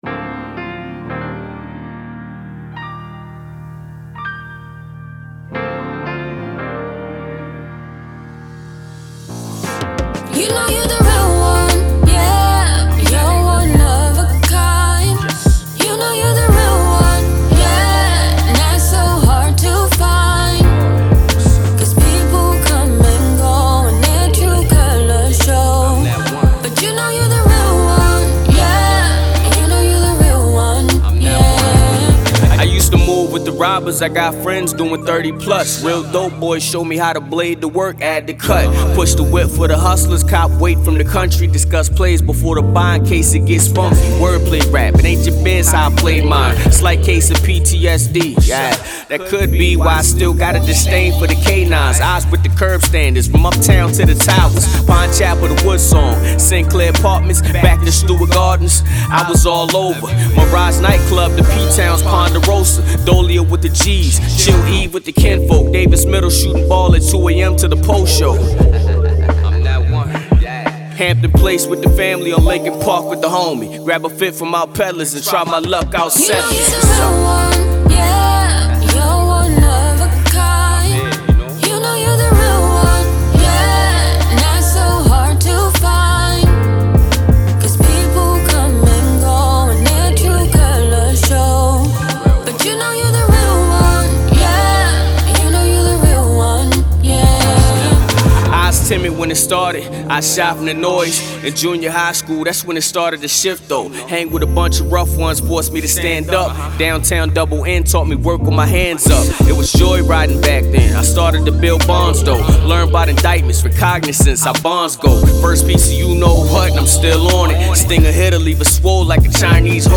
Home recording client